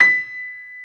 55p-pno36-B5.wav